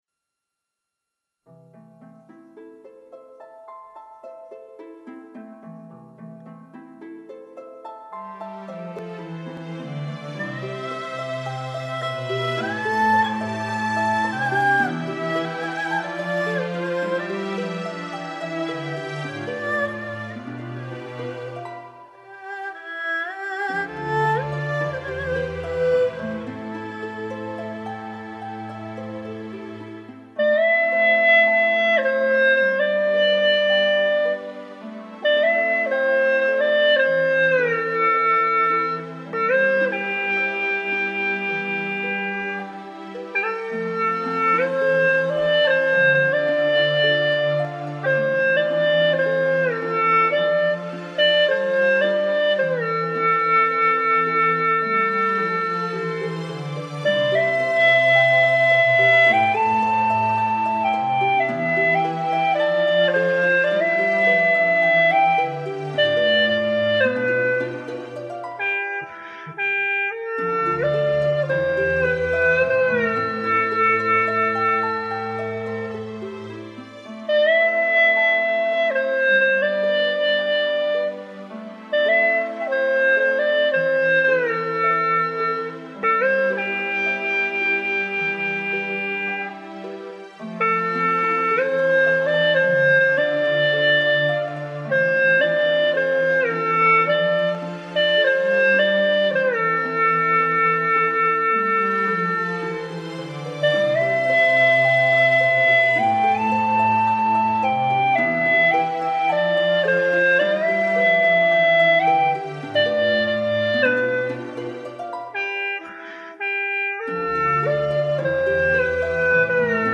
尽管自己的吹奏水平很差，刚学吹了几遍，对这首曲子还不熟，节奏掌握不好，为表达对于老师的敬意，先传到这里，请老师们指正。
姐姐吹得深情....
重录后,好多了,以前的节奏有点不稳